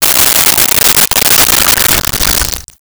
Liquid Pour Into Cup 01
Liquid Pour into Cup 01.wav